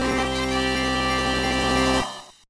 theme
Fair use music sample